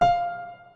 Part 5a. Piano
piano-note4.wav